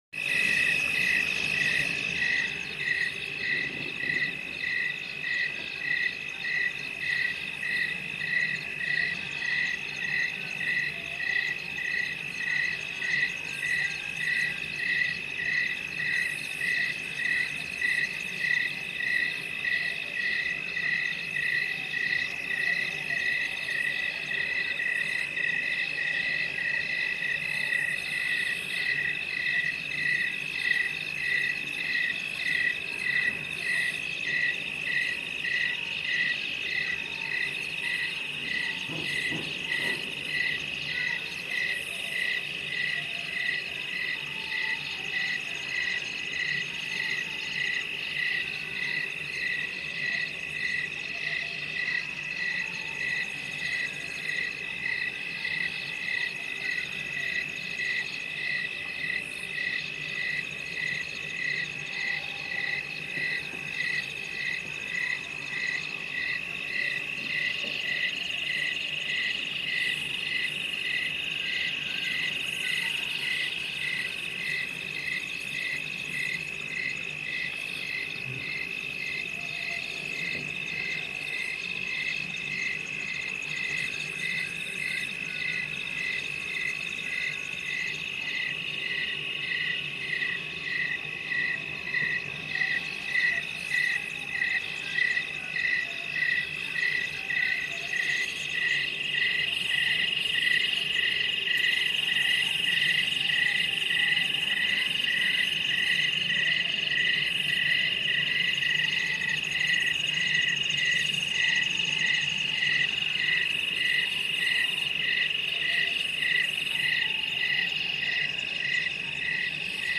Click for playback Night Ambient sounds, Crickets
Night Ambient sound of Crickets.m4a